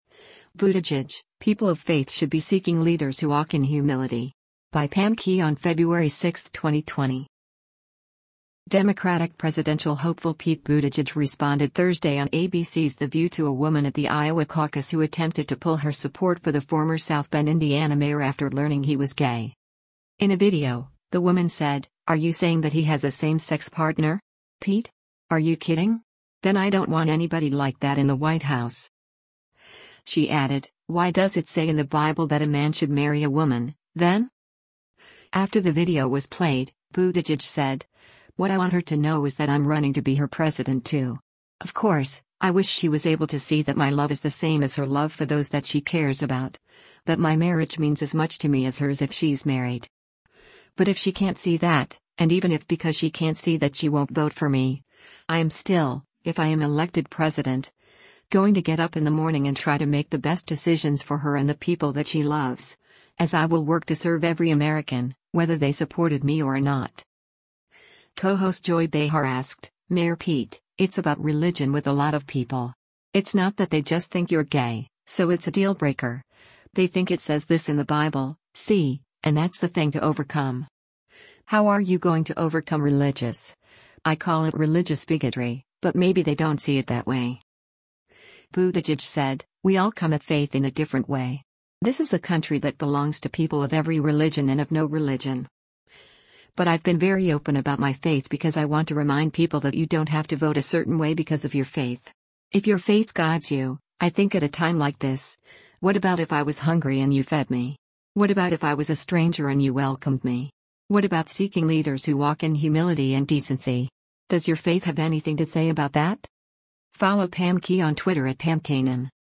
Democratic presidential hopeful Pete Buttigieg responded Thursday on ABC’s “The View” to a woman at the Iowa Caucus who attempted to pull her support for the former South Bend, IN mayor after learning he was gay.